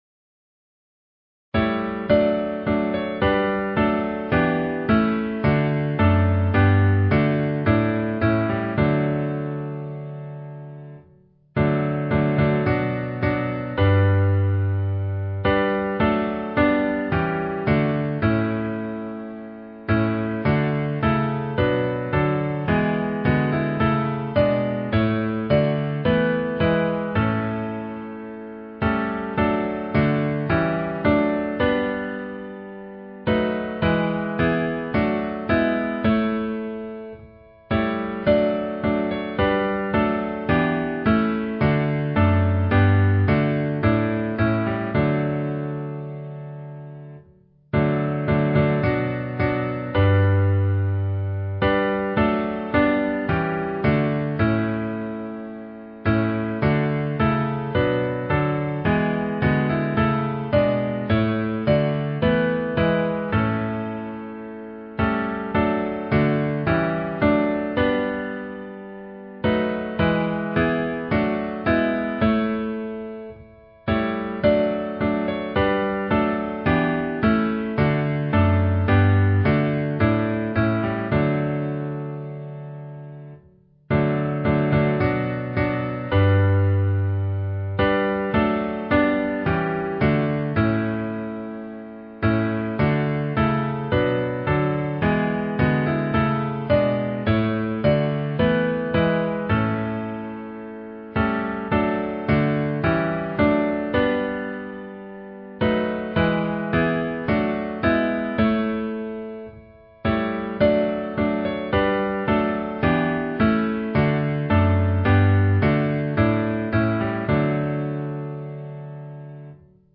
• Key: E